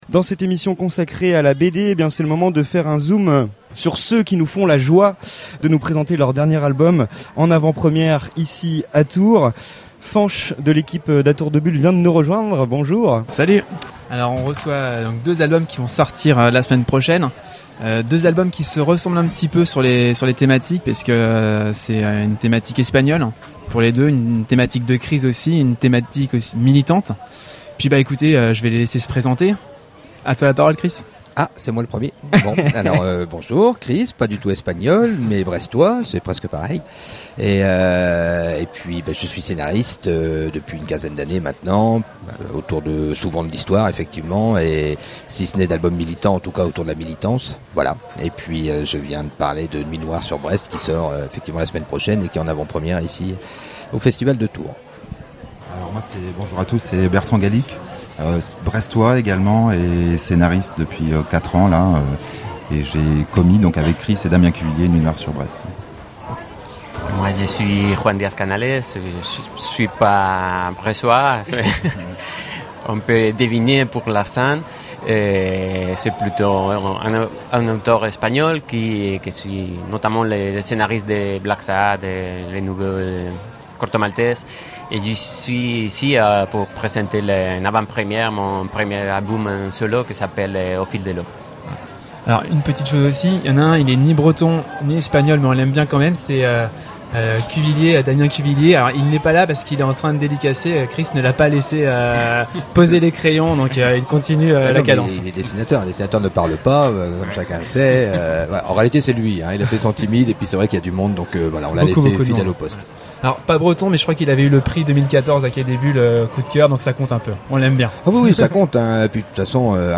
Le 10 septembre dernier, l’équipe du Festival A Tours de Bulles et Radio Campus Tours vous propose de réécouter l’émission spéciale qui s’est tenue en direct de la place Châteauneuf durant deux heures !